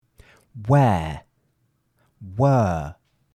where - /wɛː/ or /weə/ vs. were - /wəː/ or /wɜː/